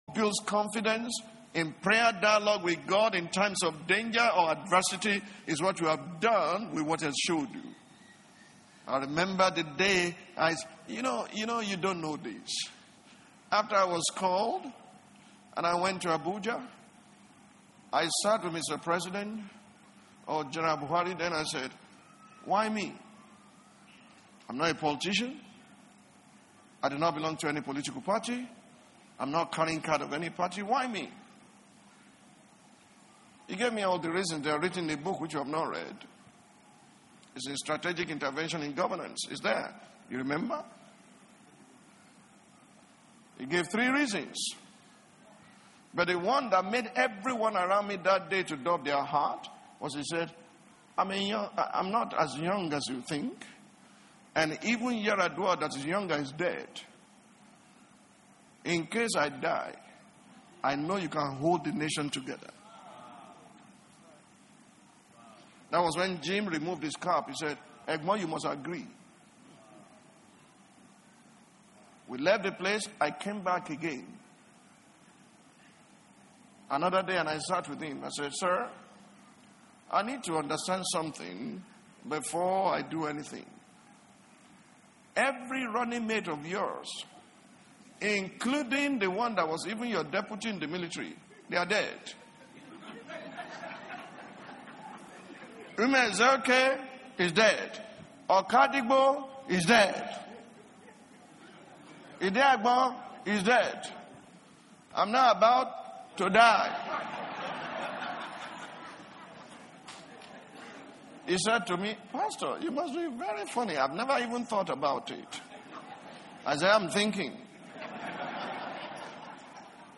Speaking to his congregation on January 3rd 2017, General Overseer of the Latter Rain Assembly, Pastor Tunde Bakare, disclosed that President Muhammadu Buhari picked him as his running mate in the 2011 election because he wanted a vice president that can hold the nation together if he passes on while in power.
Tunde-Bakare-Speech.mp3